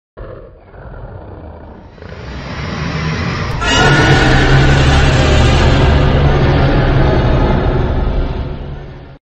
دانلود آهنگ اژدها 1 از افکت صوتی انسان و موجودات زنده
دانلود صدای اژدها 1 از ساعد نیوز با لینک مستقیم و کیفیت بالا
جلوه های صوتی